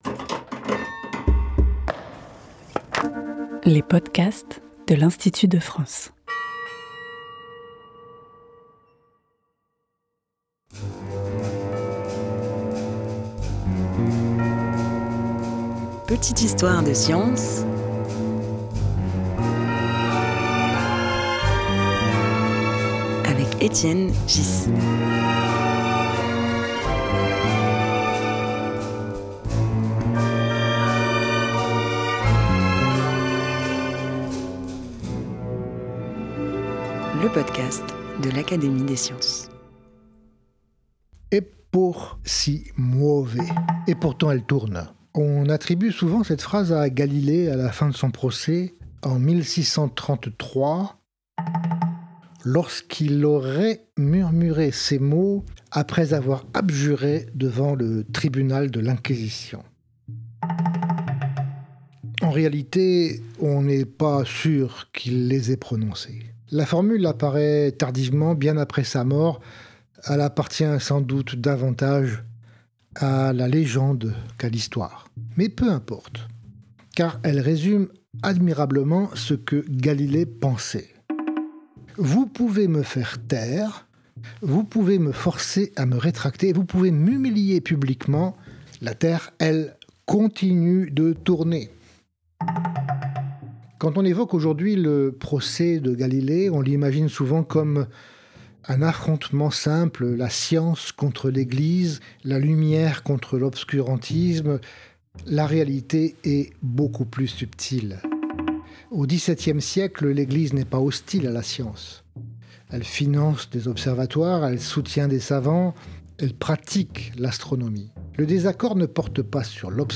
Petites histoires de science est un podcast de l'Académie des sciences, proposé par Étienne Ghys, Secrétaire perpétuel de l'Académie des sciences.